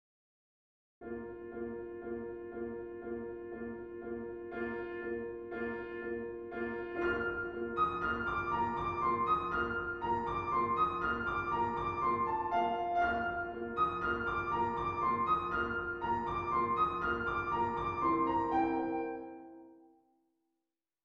Here (at a slowish tempo) are two versions of the central section of his mazurka Op. 68 No. 3.
Those pesky, ‘unnatural’ E-naturals have now been flattened (marked by the big black arrow) so that they conform to the key of B-flat major.
Trio diatonic
diatonic.mp3